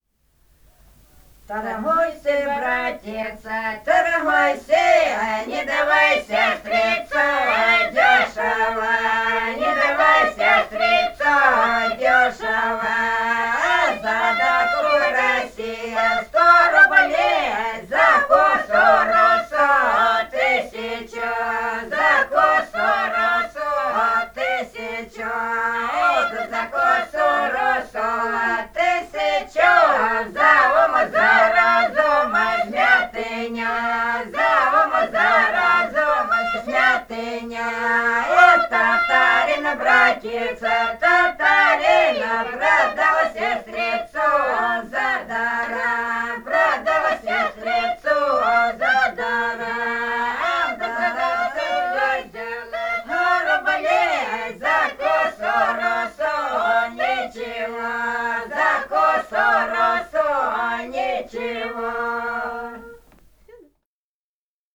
Этномузыкологические исследования и полевые материалы
Ростовская область, г. Белая Калитва, 1966 г. И0941-02]]